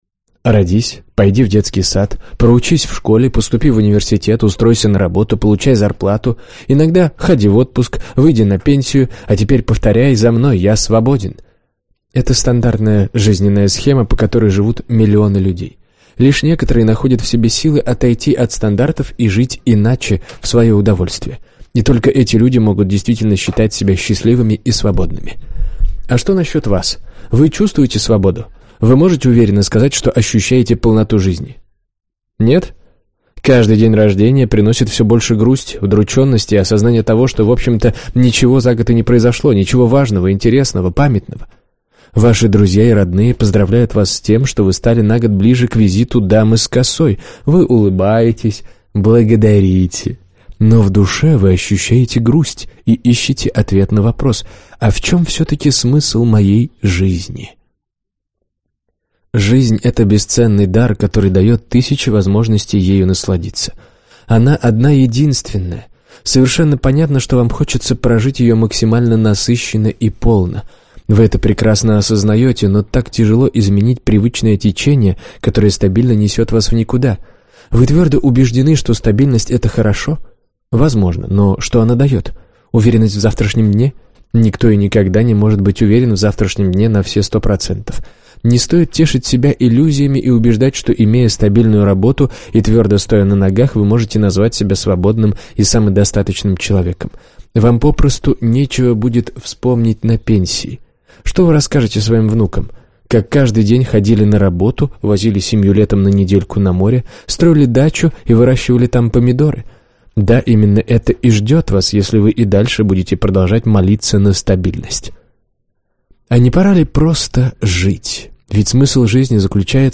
Аудиокнига Не тормози! Как выжать максимум из своей жизни | Библиотека аудиокниг